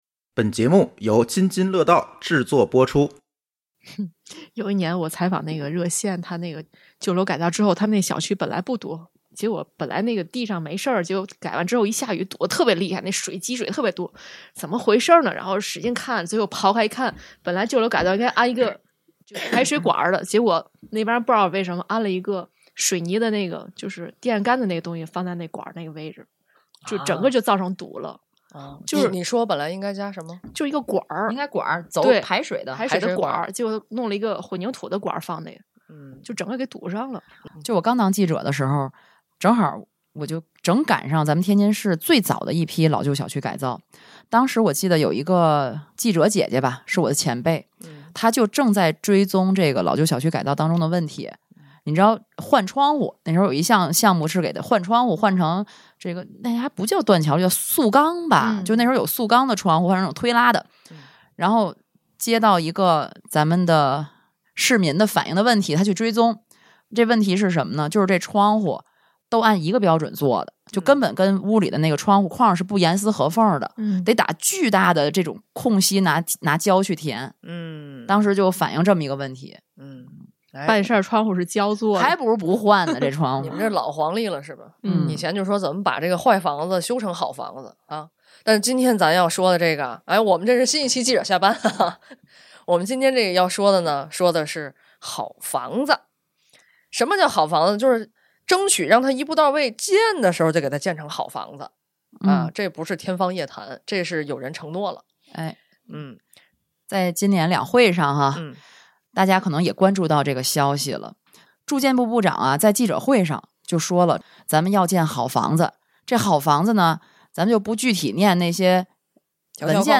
主播分享各自住房经历、装修经历、采访经历，一起盘盘未来的“好房子”！